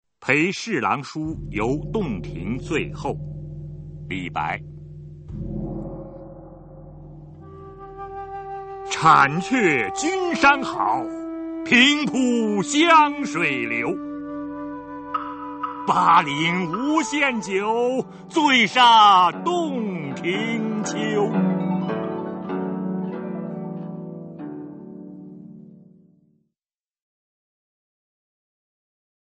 [隋唐诗词诵读]李白-陪侍郎叔游洞庭醉后 唐诗吟诵